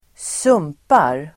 Uttal: [²s'um:par]